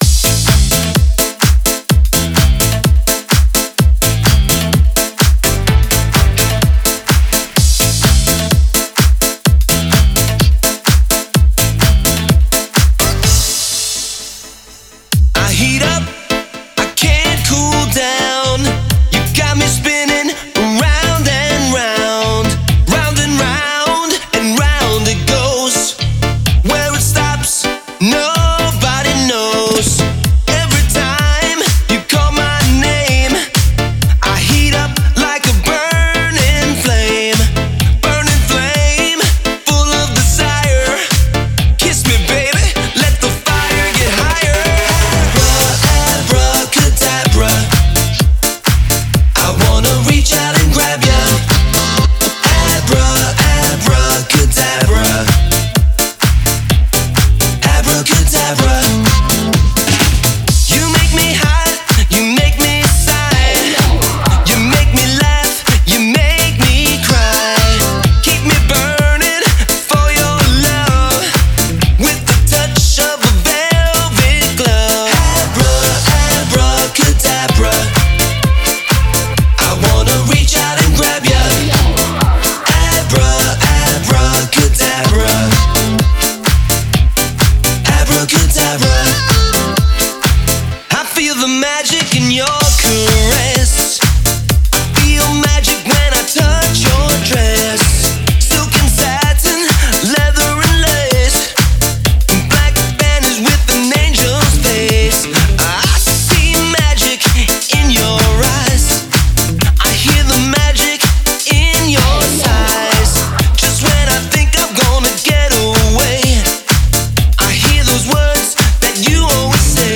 Хочу на сакуру цветущую посмотреть, а потом на пан-флейте " понаблюдать" за Полётом кондора)) Половина слов мне неизвестна, но очень интересно )